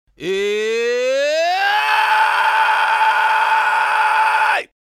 Fry Scream complexe Medium tonal
21Fry-Scream-complexe-_-Medium_tonal.mp3